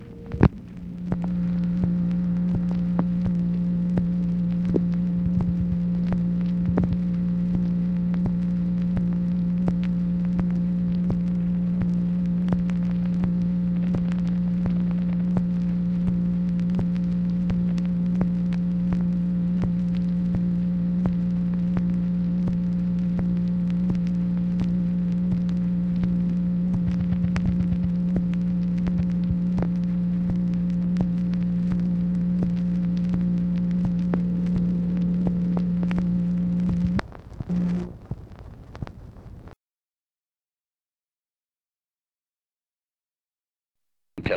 MACHINE NOISE, April 7, 1966
Secret White House Tapes | Lyndon B. Johnson Presidency